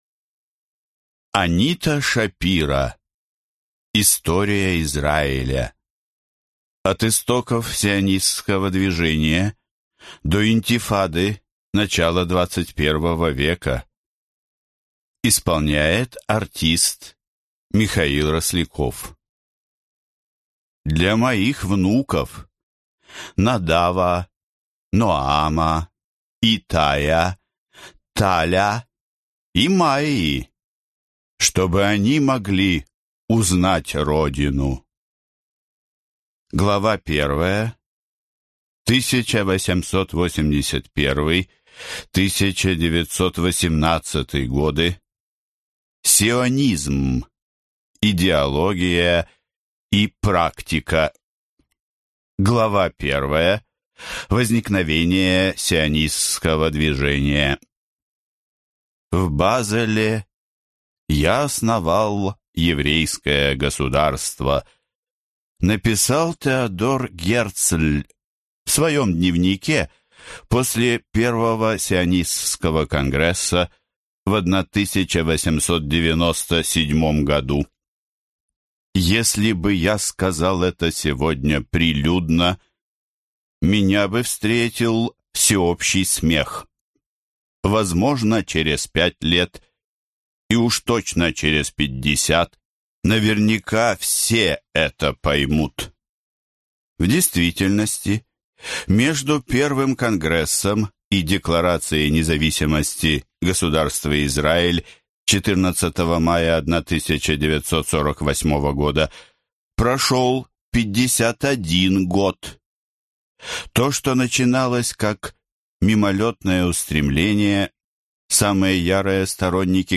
Аудиокнига История Израиля. От истоков сионистского движения до интифады начала XXI века | Библиотека аудиокниг